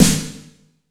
AMBIENT S9-R 2.wav